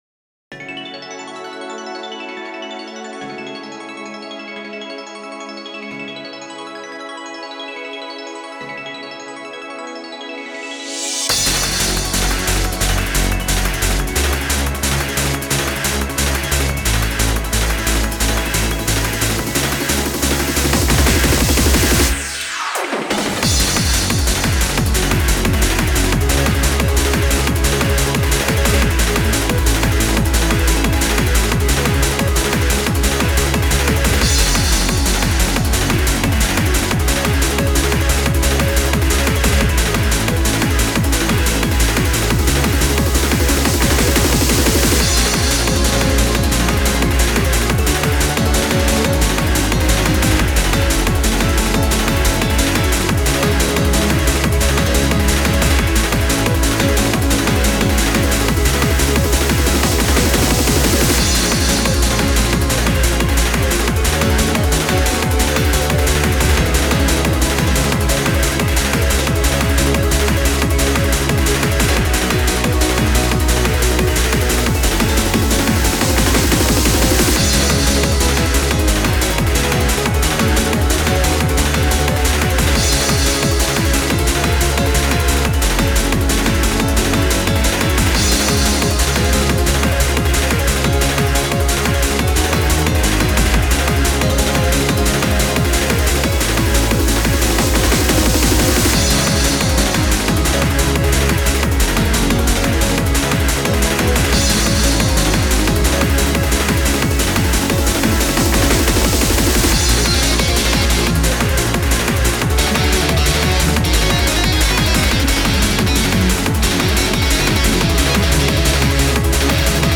Trance Core mix
(Hi-speed classic style)
結果を先に述べるとトランスコア化させてだいぶ満足な仕上がりになったと思います。